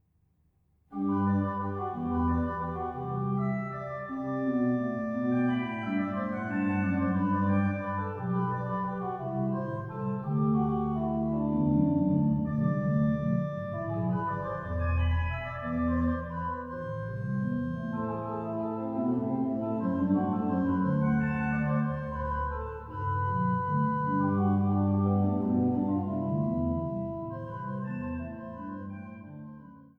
Sie greift auf Tugenden des barocken Orgelbaus zurück, ohne jedoch eine barocke Stilkopie darzustellen. Die Vielfarbigkeit der nichtsdestotrotz sehr übersichtlichen Disposition ermöglicht eine beeindruckende musikalische Transparenz, und für die Wiedergabe von Werken Johann Sebastian Bachs erweist sich das Instrument als überaus geeignet.